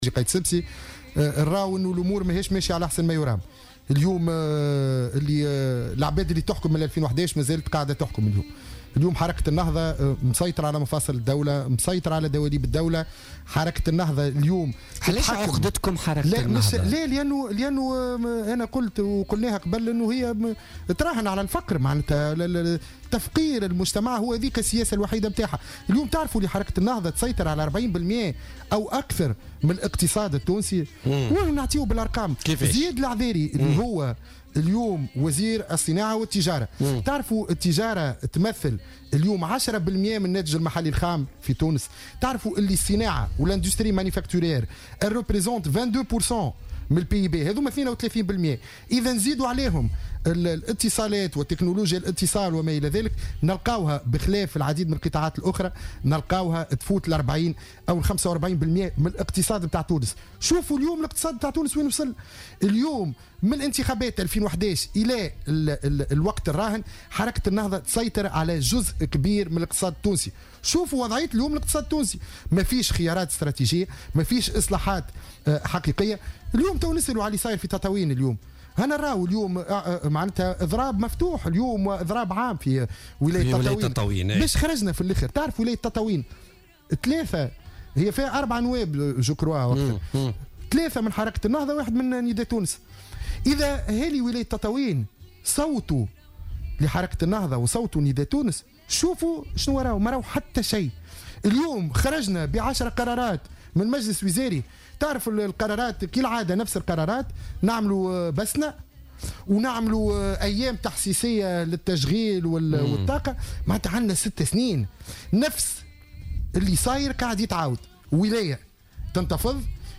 وقال ضيف "بوليتيكا" إن النهضة تسيطر على 40 بالمائة من الاقتصاد من خلال وزارئها بمختلف الوزارات المعنية بالاقتصاد.